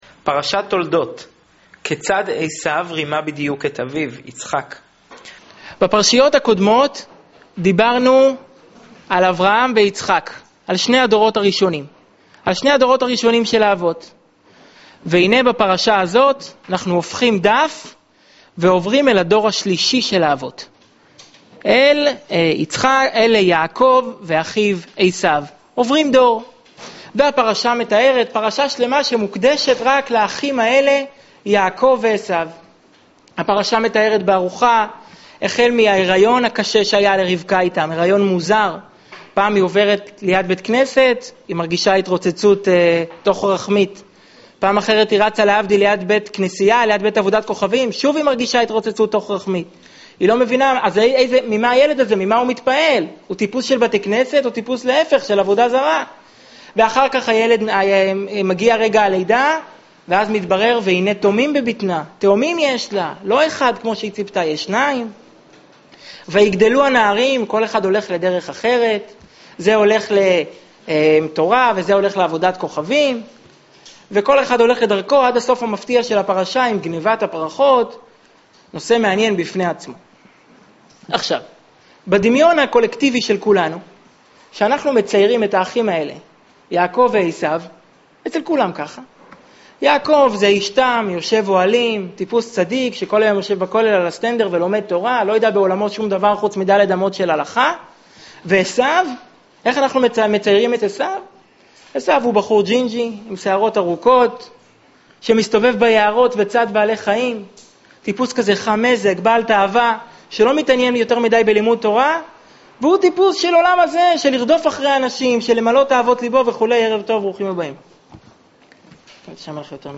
שיעור בצביעות: כיצד עשו שיקר את אביו?
שנמסר בביהכנ"ס חב"ד בראשל"צ